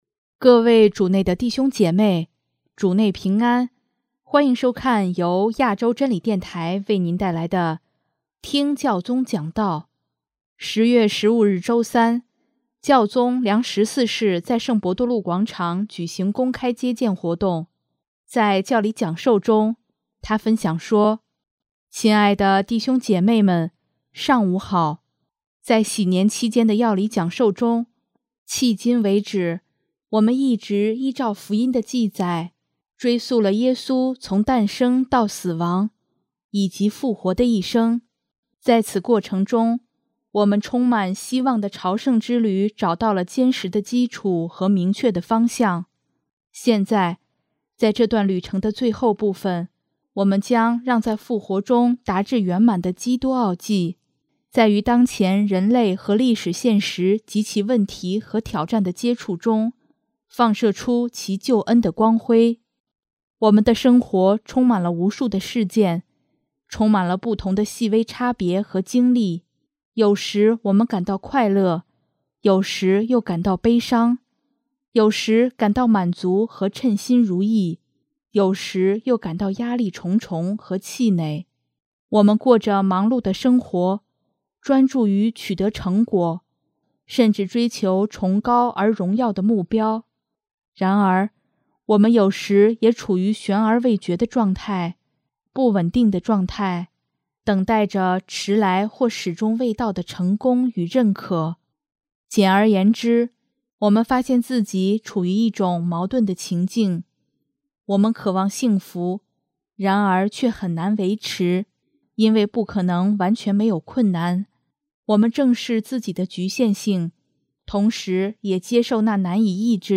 首页 / 新闻/ 听教宗讲道
10月15日周三，教宗良十四世在圣伯多禄广场举行公开接见活动。